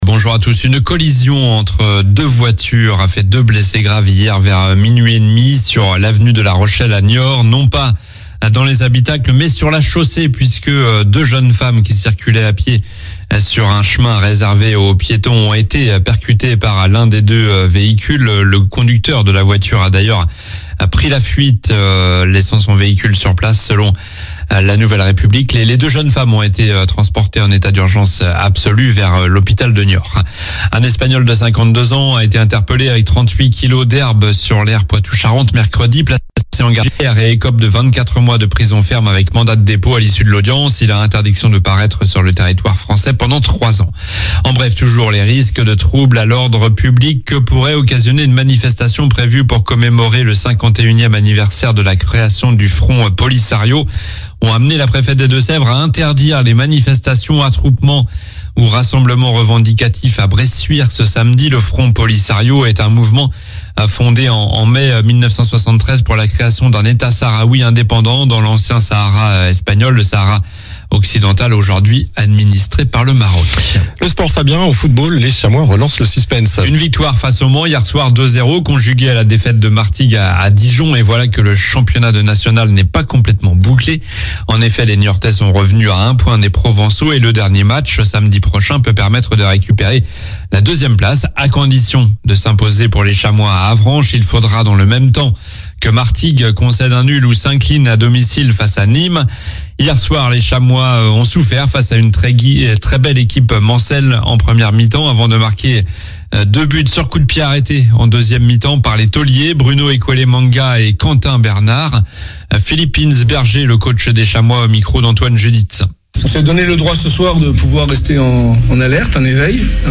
Journal du samedi 11 mai (matin)